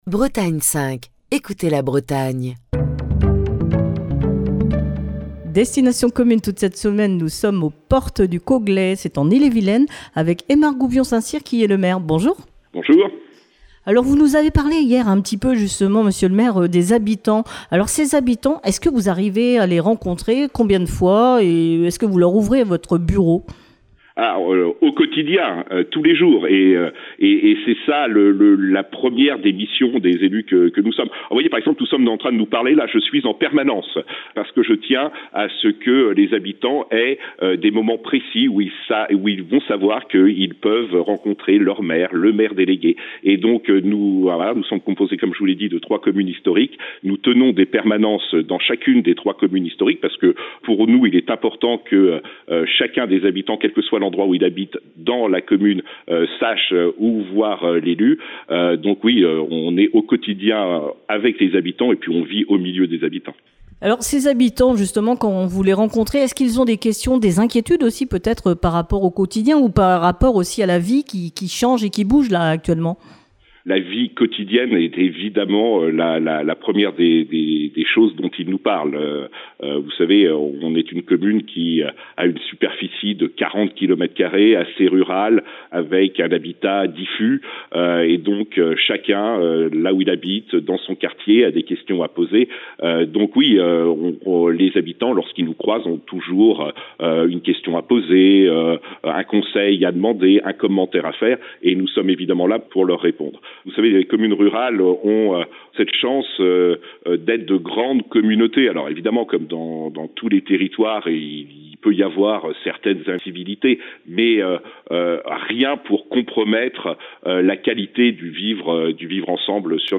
Destination commune passe la semaine aux Portes du Coglais, en Ille-et-Vilaine. Aymar de Gouvion Saint-Cyr, maire des Portes du Coglais, présente sa commune